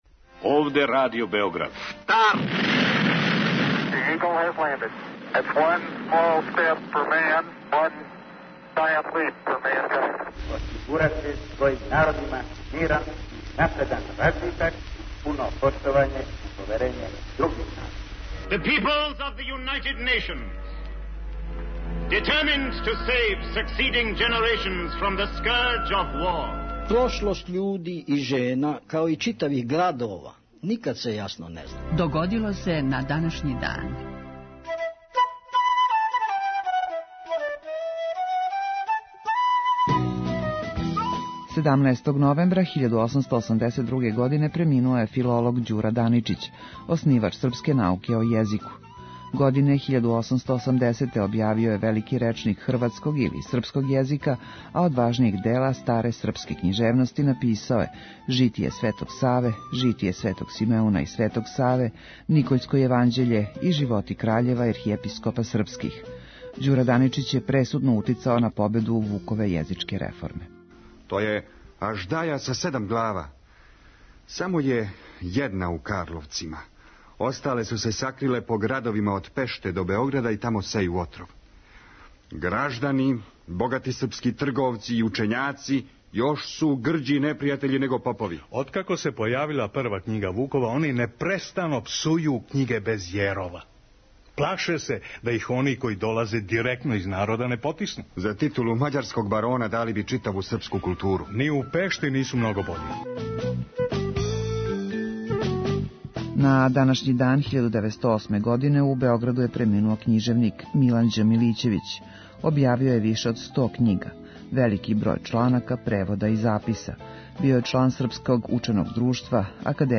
У 5-томинутном прегледу, враћамо се у прошлост и слушамо гласове људи из других епоха.